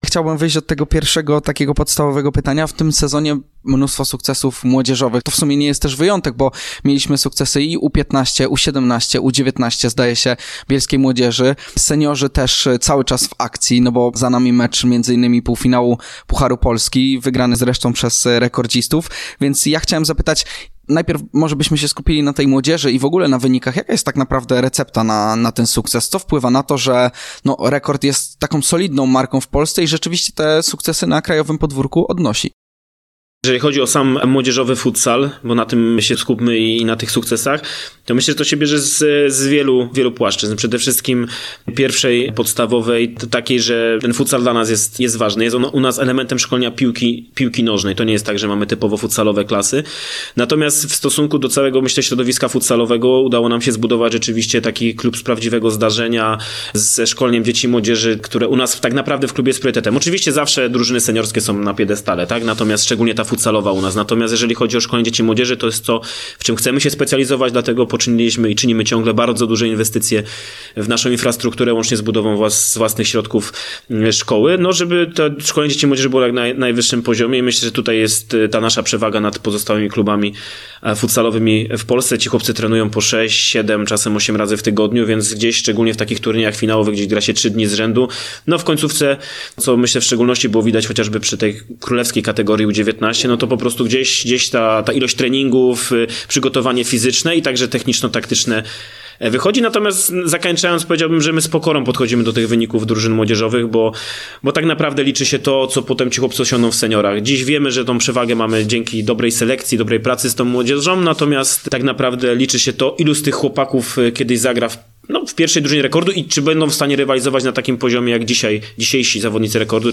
Rozmowa wielowątkowa